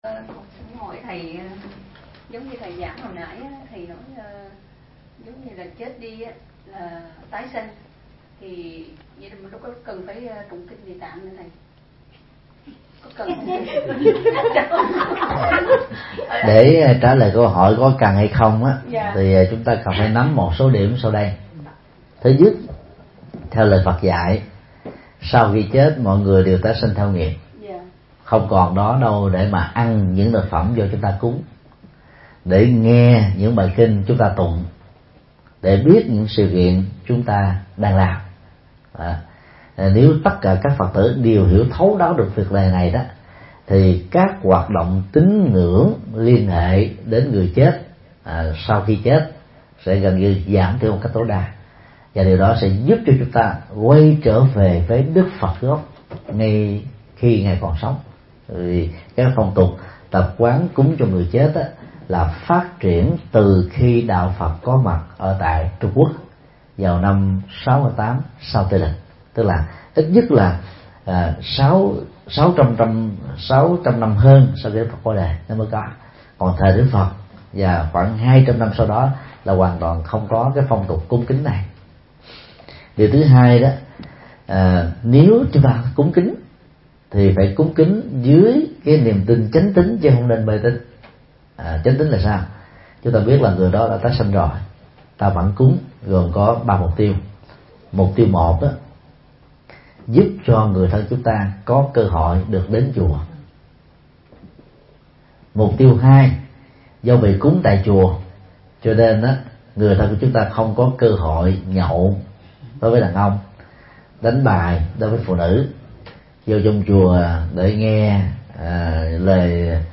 Vấn đáp: Hướng dẫn tụng kinh Địa Tạng sao cho đúng